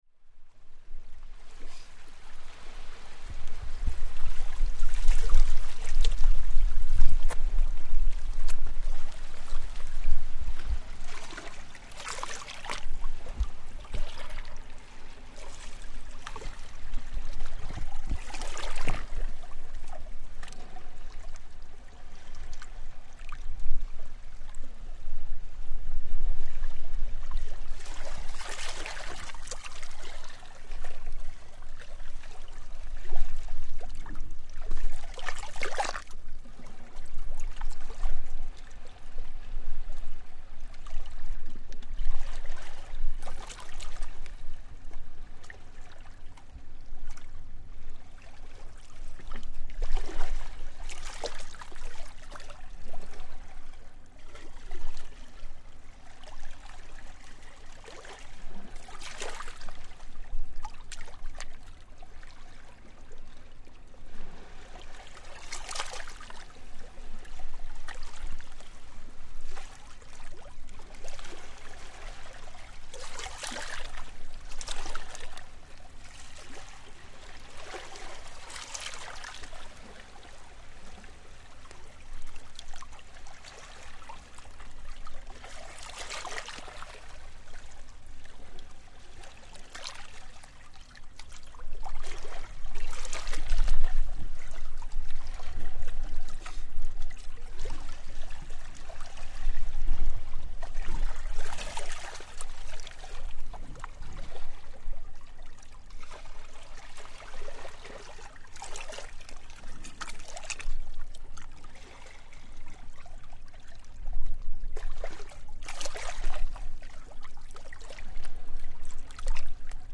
Gentle waves on Pirita Beach
Water gently laps against rocks next to a long stone quay on Pirita Beach, one of Tallinn's semi-urban beaches.